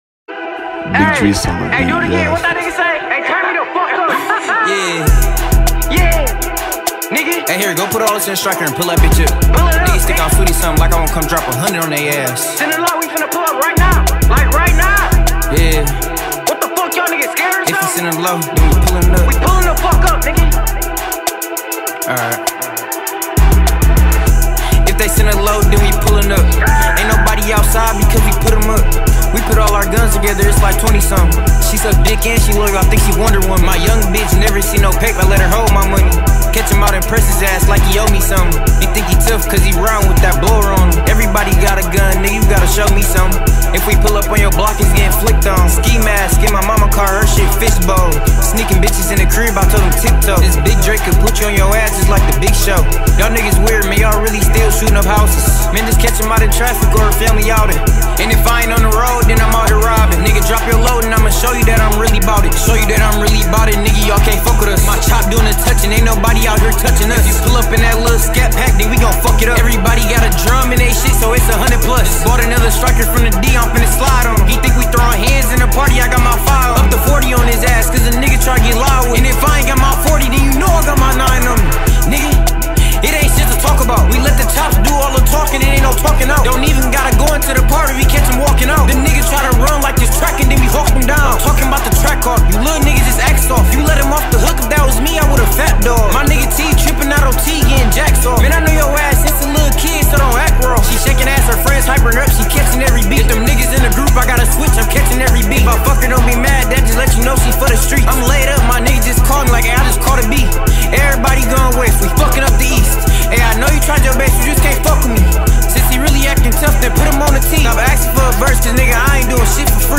сочетая элементы хип-хопа и мелодичного рэпа.